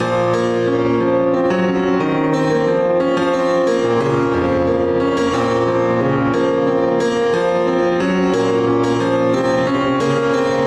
深入的钢琴循环
Tag: 90 bpm Hip Hop Loops Piano Loops 1.80 MB wav Key : Unknown